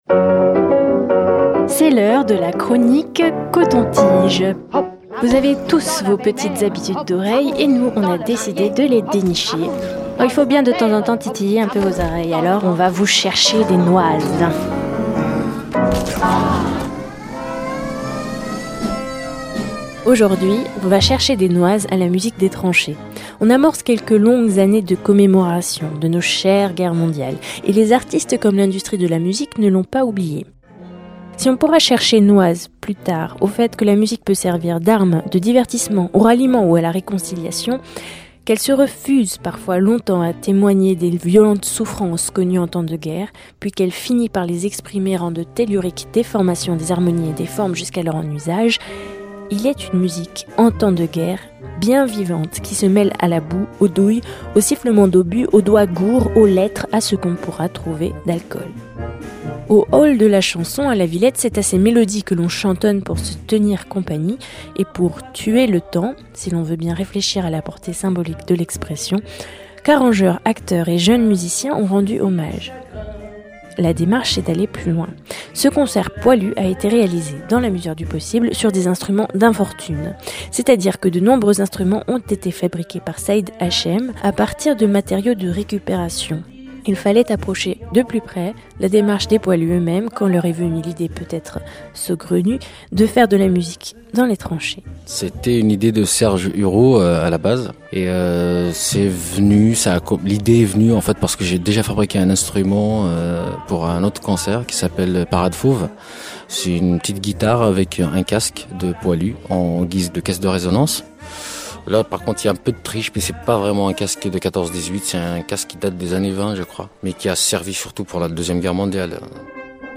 Concert Poilu, musique des tranchées.
Pourquoi faire de la musique en temps de guerre? Voilà la question que pose le spectacle "Concert Poilu (sur instruments d'infortune)" qui s'est joué les 22 et 23 novembre 2014 au Hall de la Chanson, à la Villette.